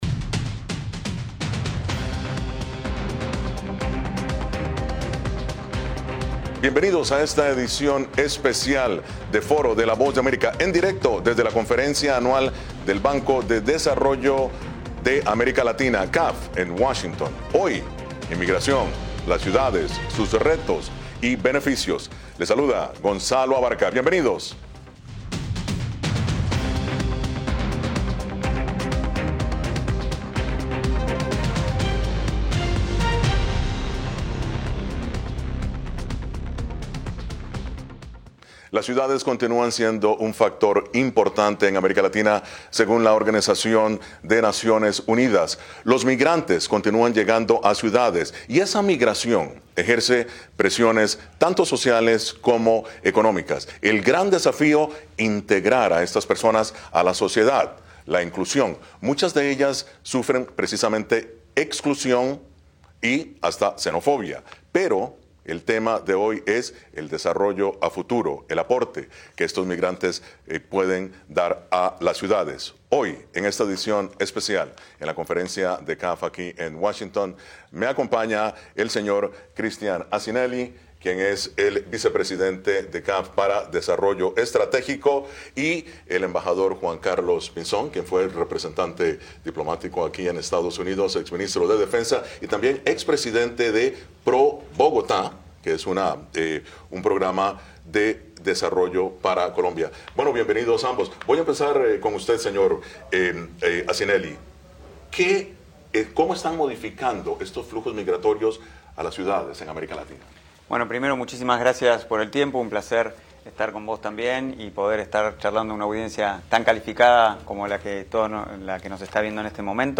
La migración aún presenta grandes desafíos en las Américas, pero también beneficios económicos para las grandes ciudades que son las más atractivas para los migrantes. Desde la Conferencia anual CAF, analizamos las oportunidades y beneficios de una rápida inclusión económica de los migrantes.